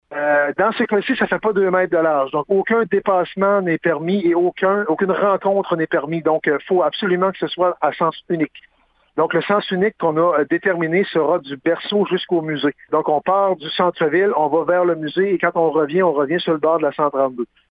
Les marcheurs pourront aussi avoir accès à la promenade Jacques Cartier, mais avec des règles précises: